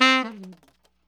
TNR SHFL C4.wav